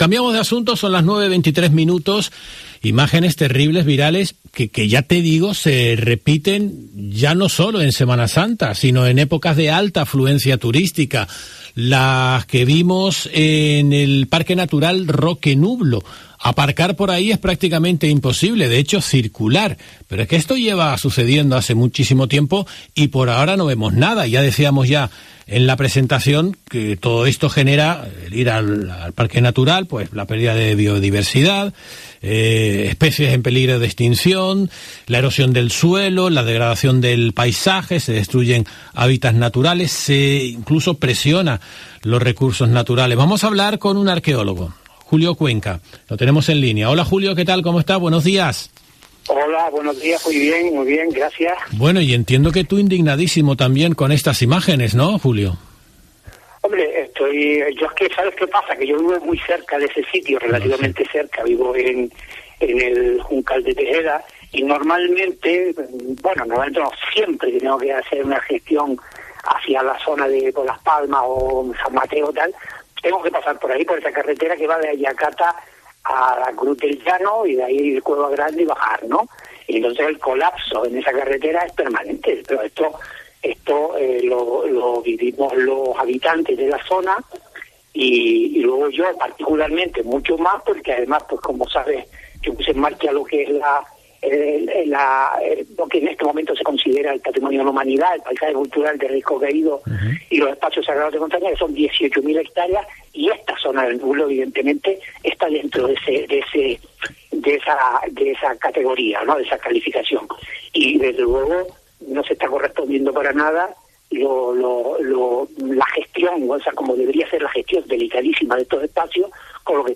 En nuestros micrófonos asegura que a pesar de que se declaró a finales de los años 80 al Roque Nublo como un espacio natural y protegido, llevan 40 años sin poner en marcha las normas de conservación que ya están establecidas.